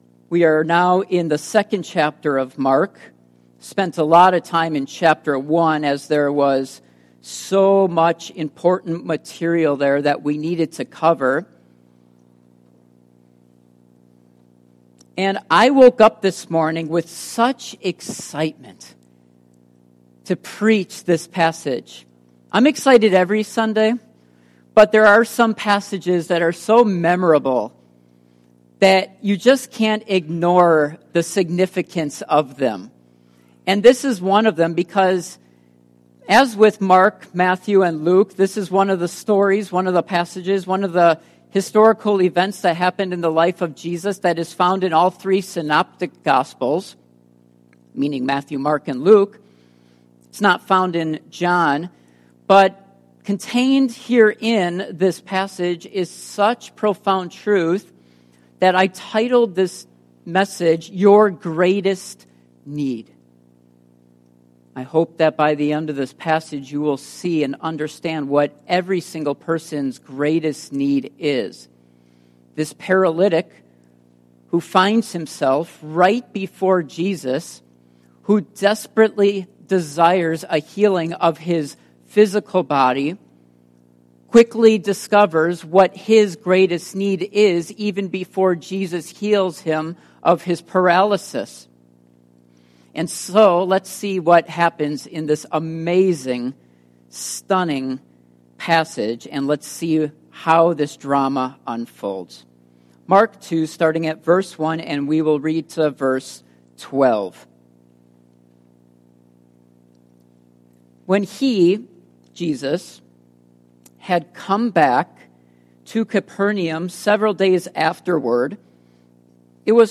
Passage: Mark 2:1-12 Service Type: Morning Worship